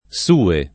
S2a]; pl. m. suoi [SU0i], pl. f. sue [
S2e] — pop. tosc. su’ (in ant., a volte scritto suo), in pròclisi, per tutt’e quattro le forme (fuor di procl., sua anche come pl. m. e f.)